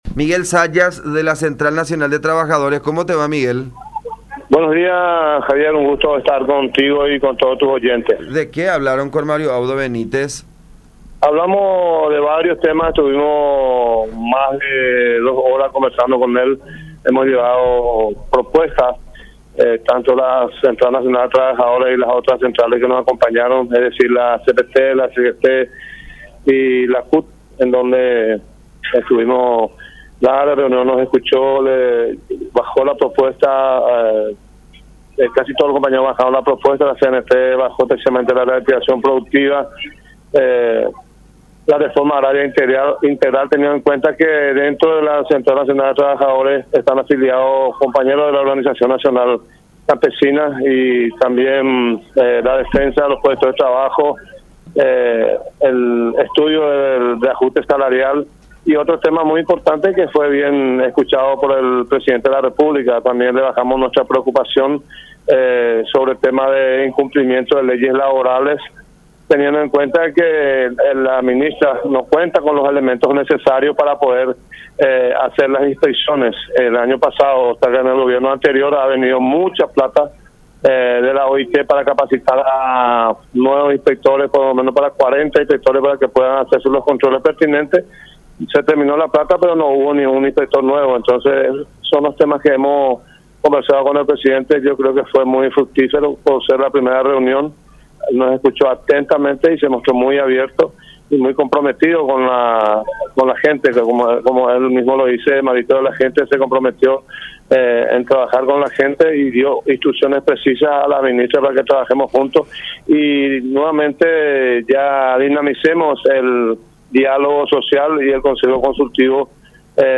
“Nos escuchó atentamente y se mostró muy comprometido”, expuso el dirigente sindical en conversación con La Unión, resaltando que la reunión fue muy fructífera por ser la primera vez.